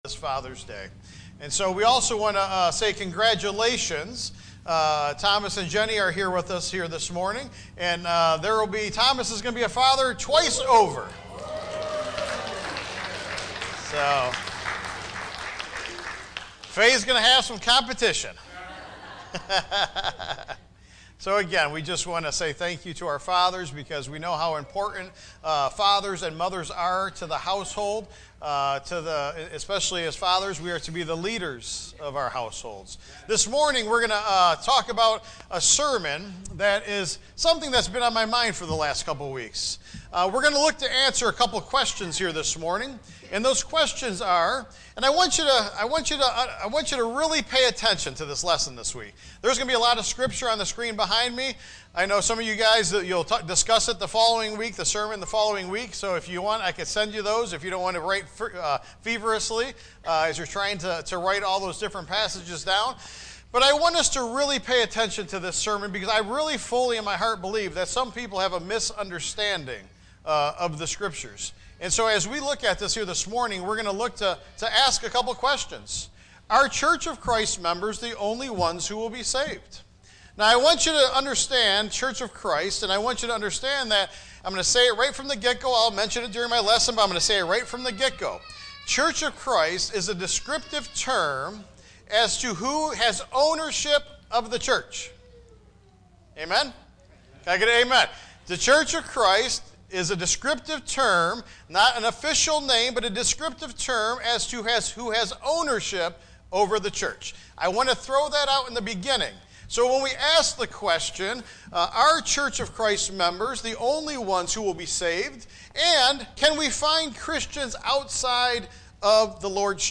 Tagged with sermon